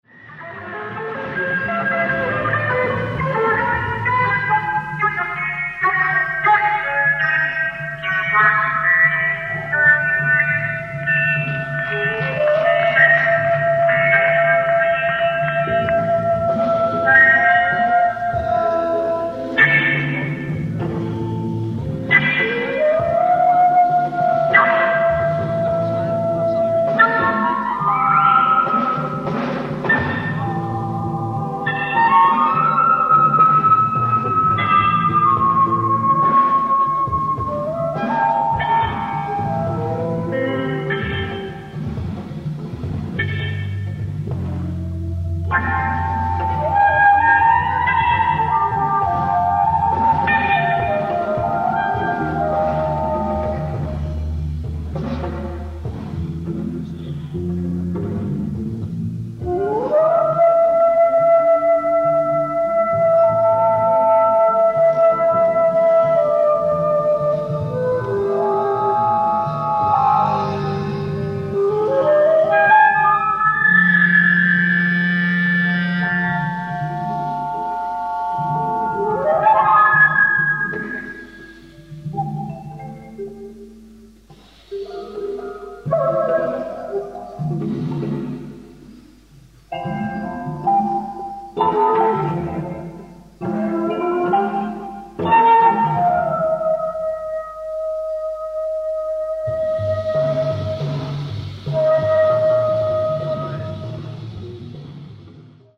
ライブ・アット・デ・ドゥーレン、ロッテルダム、オランダ 11/17/1973
海外マニアによるピッチ修正済レストア音源を初収録！！
※試聴用に実際より音質を落としています。